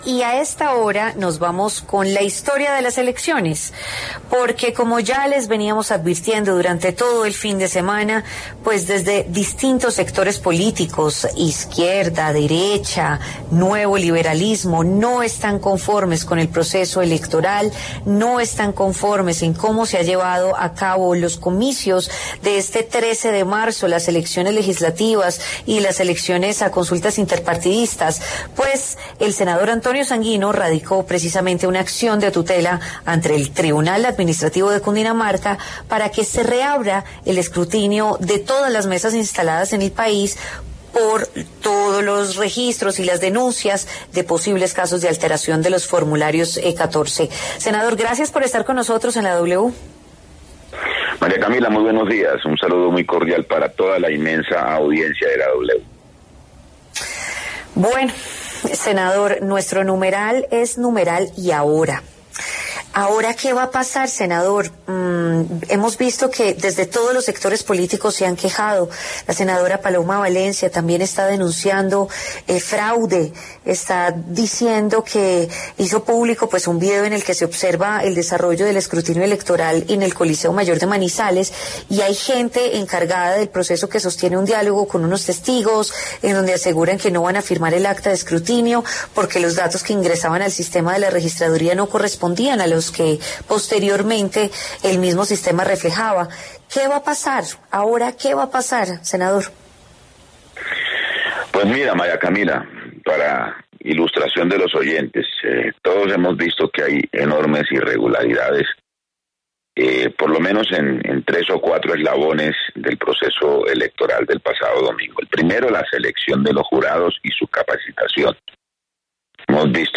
El senador Antonio Sanguino se refirió en La W a la polémica que rodea el escrutinio de las elecciones legislativas.